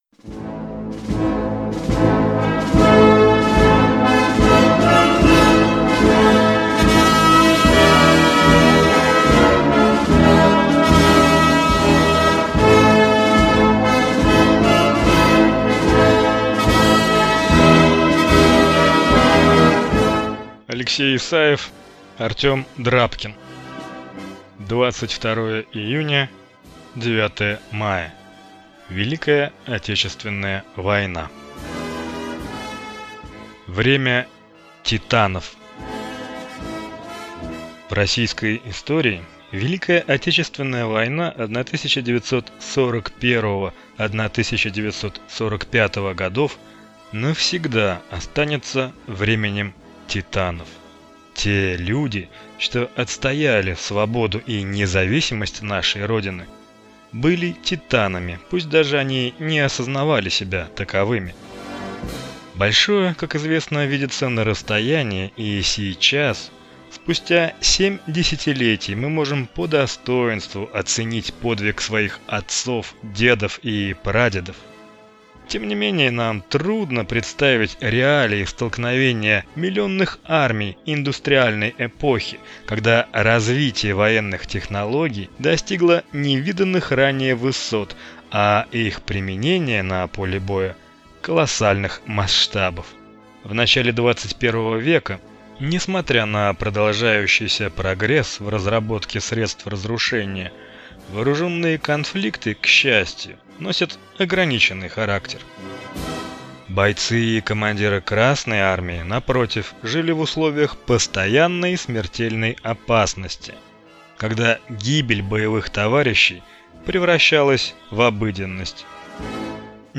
Аудиокнига 22 июня – 9 мая. Великая Отечественная война | Библиотека аудиокниг